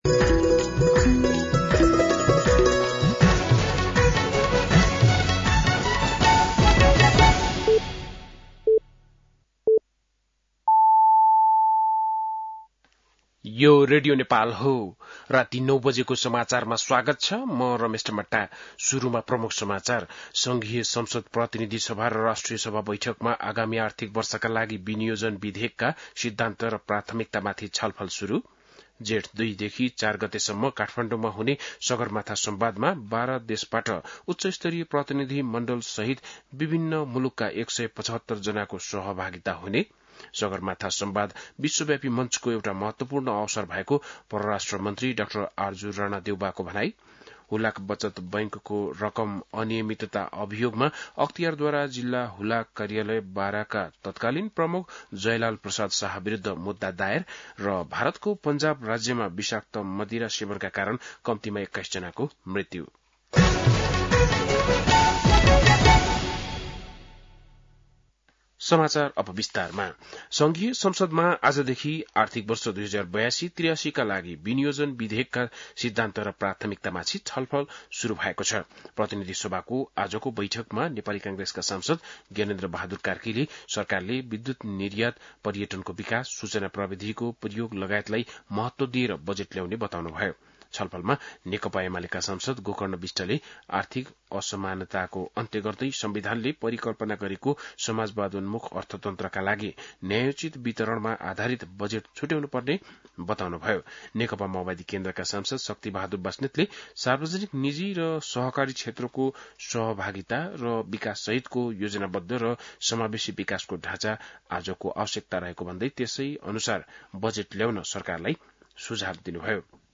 बेलुकी ९ बजेको नेपाली समाचार : ३० वैशाख , २०८२